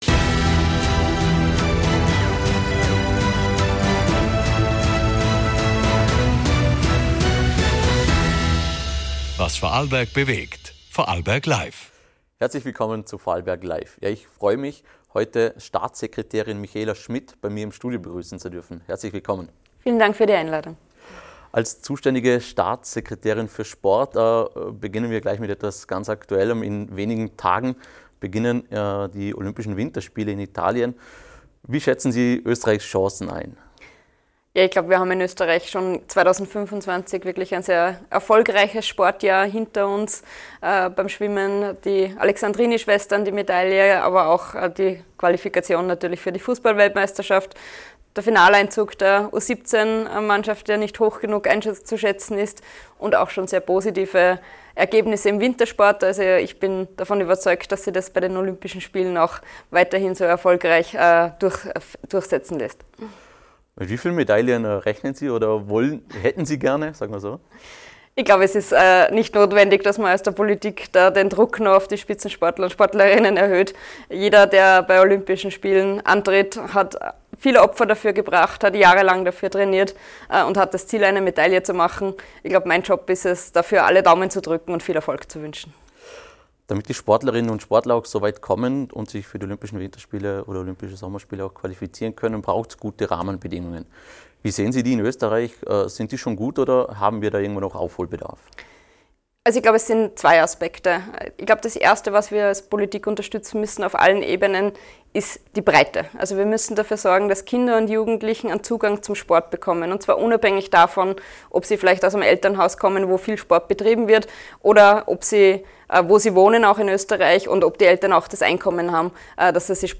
Was braucht Österreich, damit Sport groß wird — von der Basis bis zu den Olympischen Spielen? Staatssekretärin Michaela Schmidt spricht mit Moderator*in im Studio über aktuelle sportpolitische Herausforderungen und Zukunftsfragen.